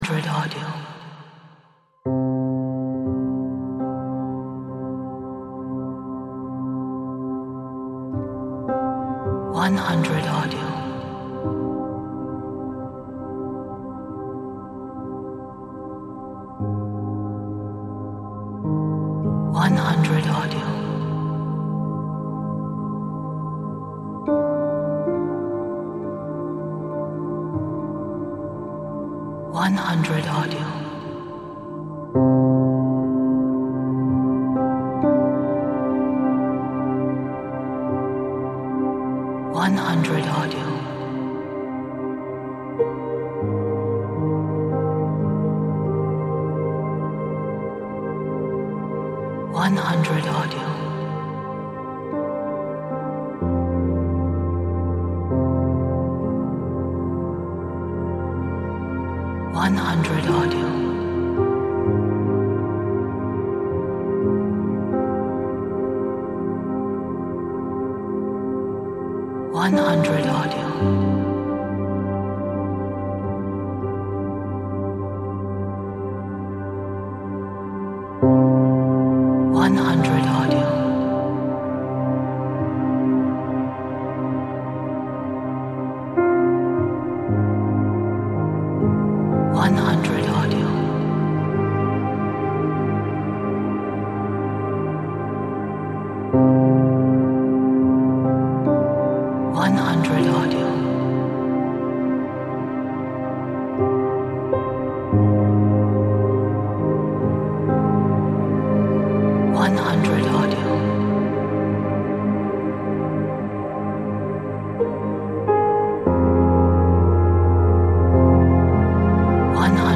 a haunting, ethereal and beautiful ambient piano track.